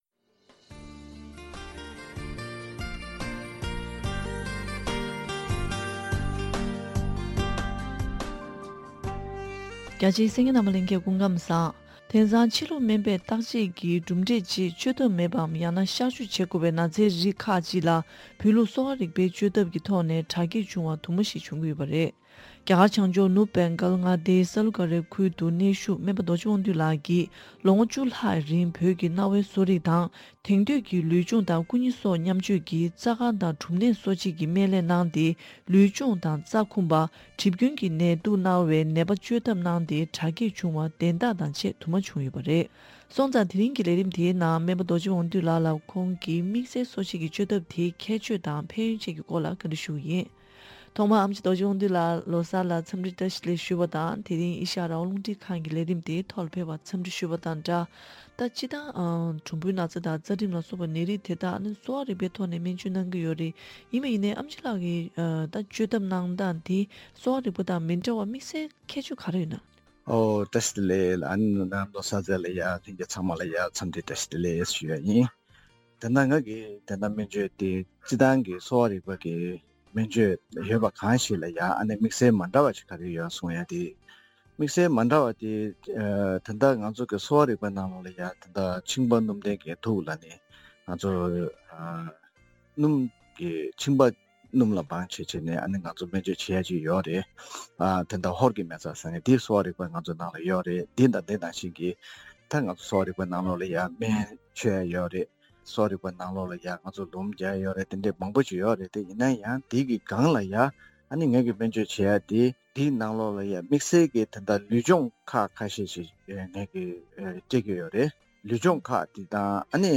བཀའ་འདྲི་ཞུས་པའི་ལས་རིམ